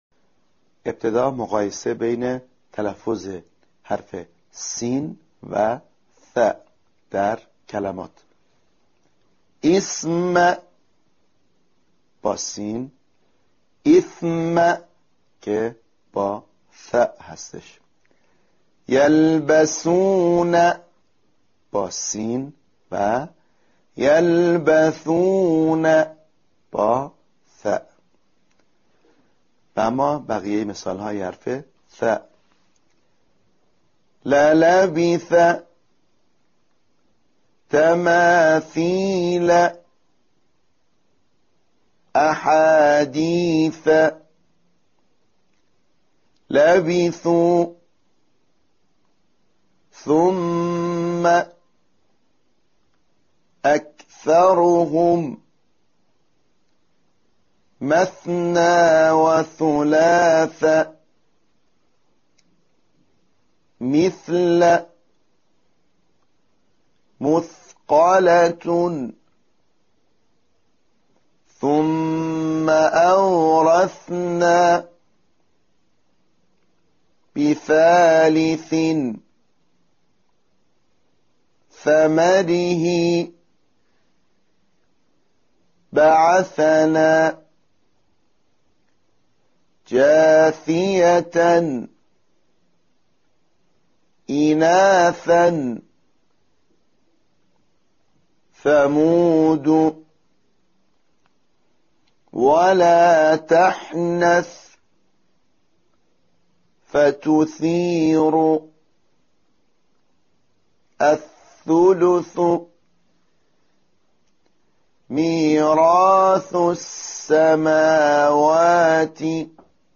تمرین عملی_مرحله ۲
💠تلفظ حرف «ث»💠